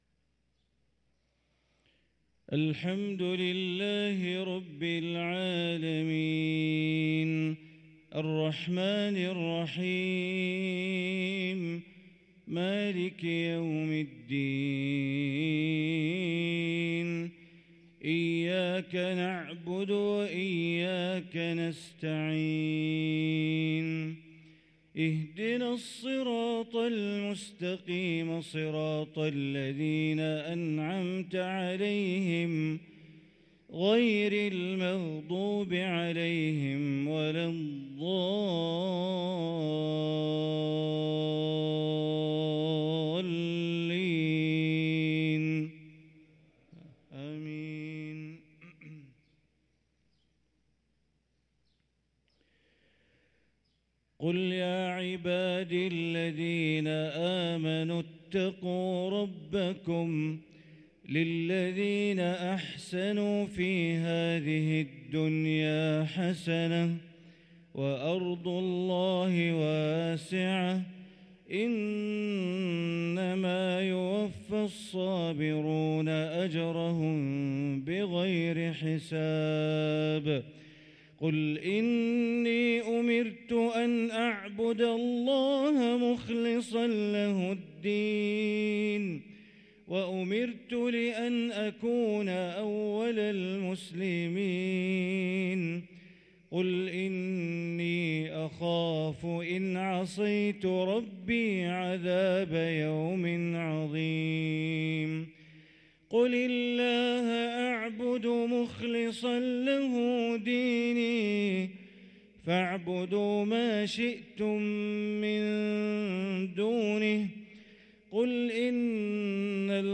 صلاة الفجر للقارئ بندر بليلة 2 شوال 1444 هـ
تِلَاوَات الْحَرَمَيْن .